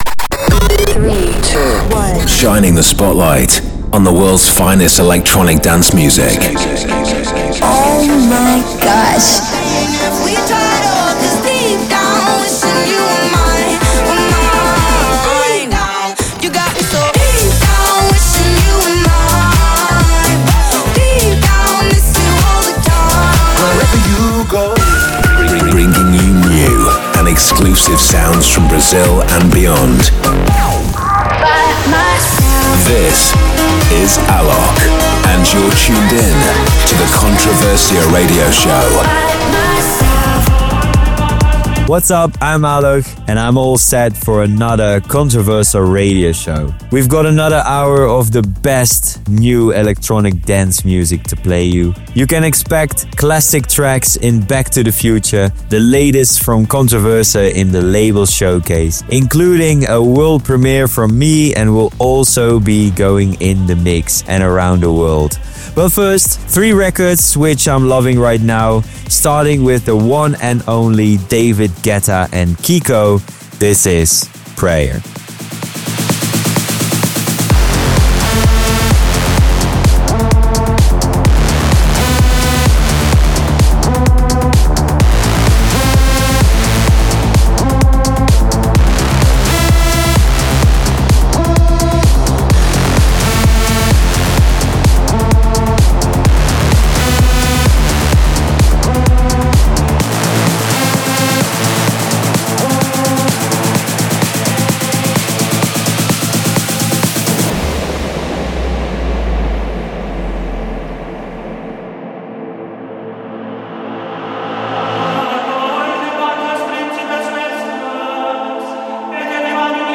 music DJ Mix in MP3 format
Genre: Electro House